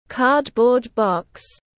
Hộp các-tông tiếng anh gọi là cardboard box, phiên âm tiếng anh đọc là /ˈkɑːd.bɔːd bɒks/
Cardboard box /ˈkɑːd.bɔːd bɒks/